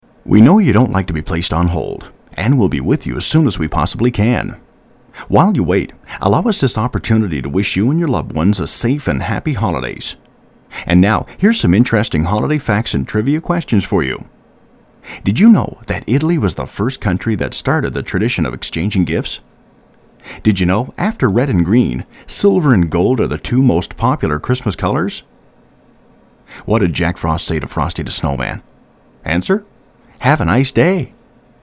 "on hold" voicer